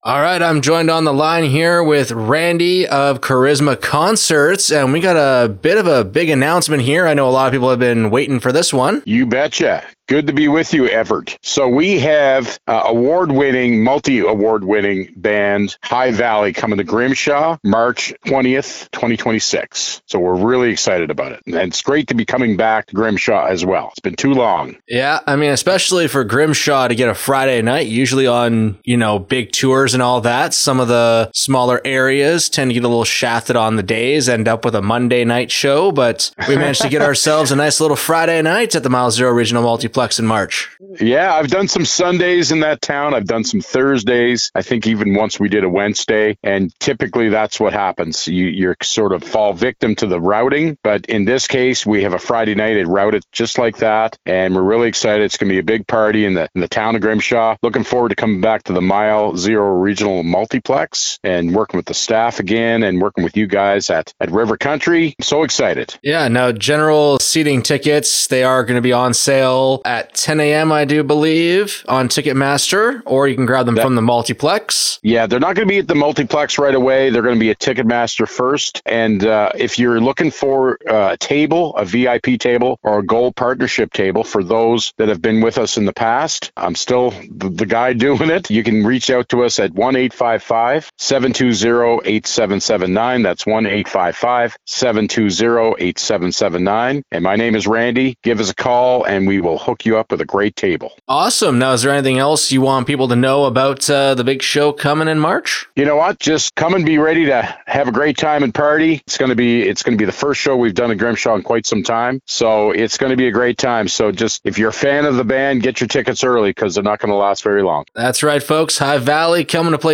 high-valley-announcment.wav